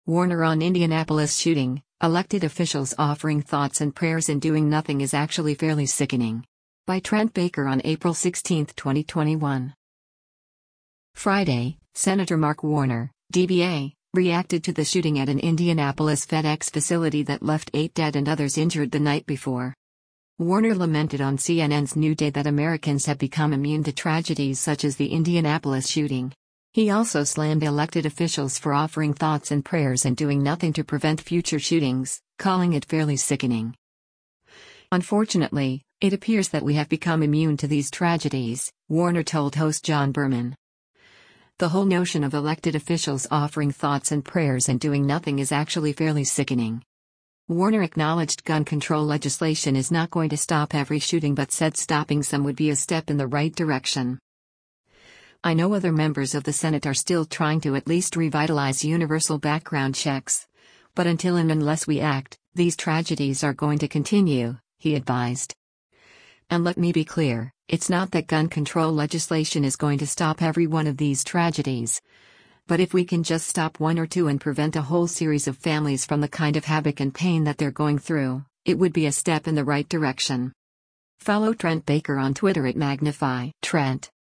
Warner lamented on CNN’s “New Day” that Americans “have become immune” to tragedies such as the Indianapolis shooting.